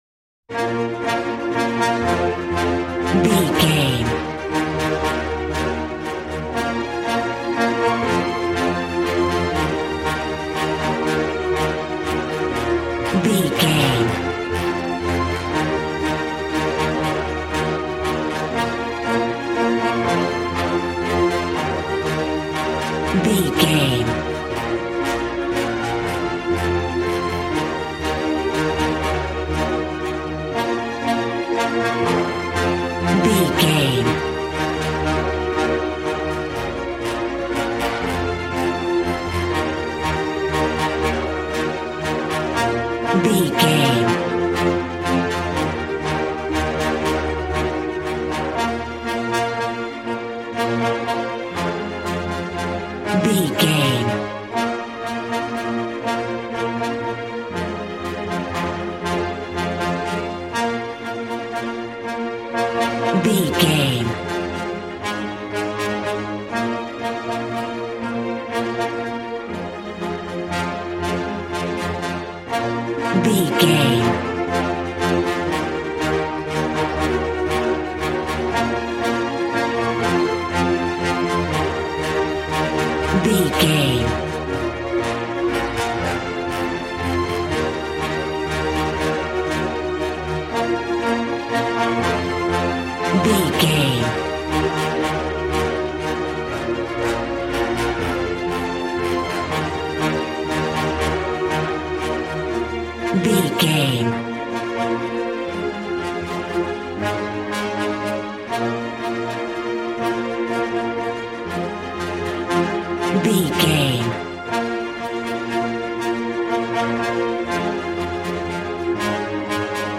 A classical music mood from the orchestra.
Regal and romantic, a classy piece of classical music.
Ionian/Major
B♭
regal
cello
violin
strings